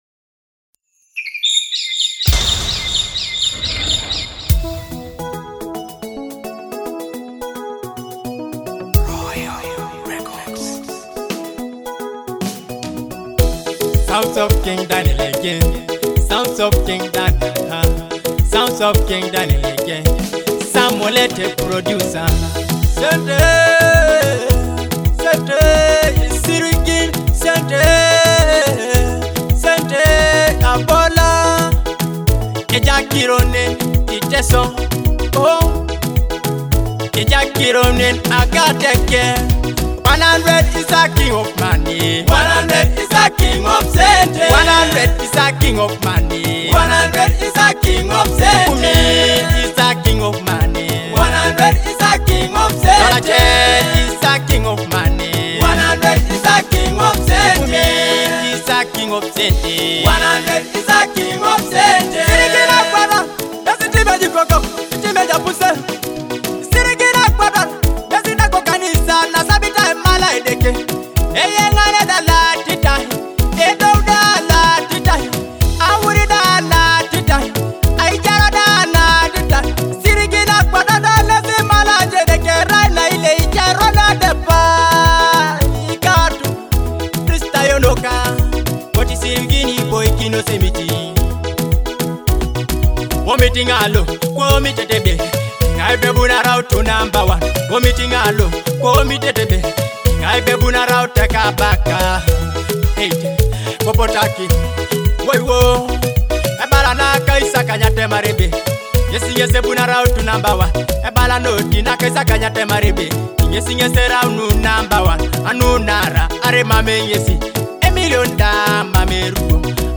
a thought-provoking Teso song about money